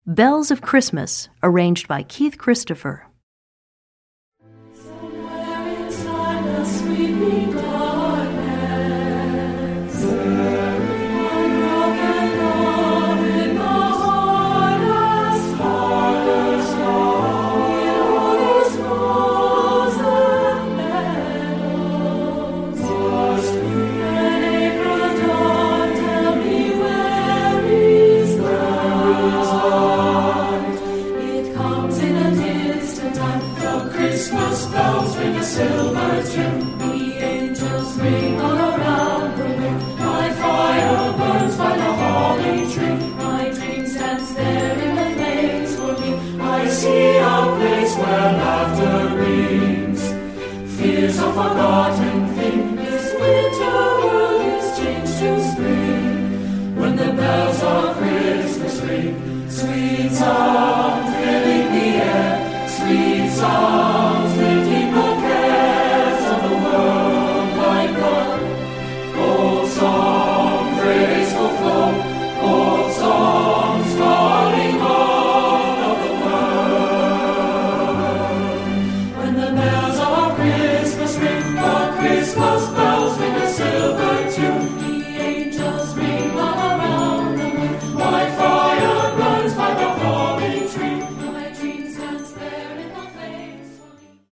Voicing: Accompaniment CD